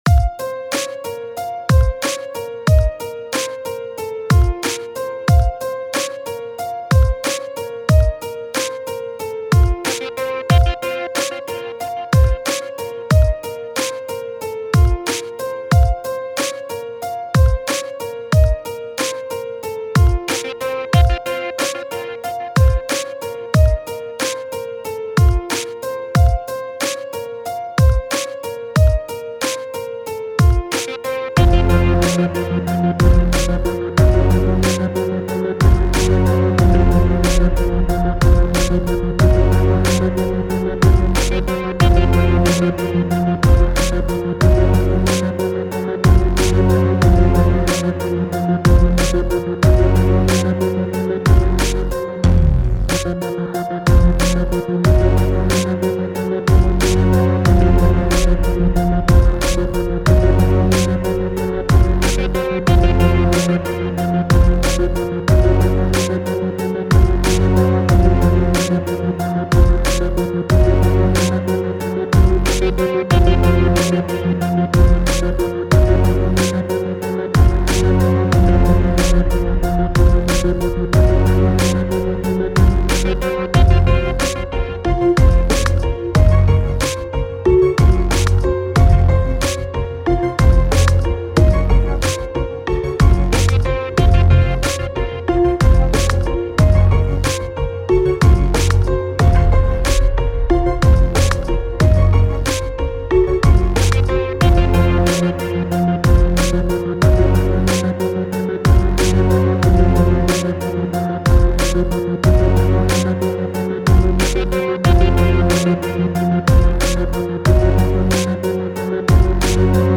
بدون ووکال
دانلود بیت رپ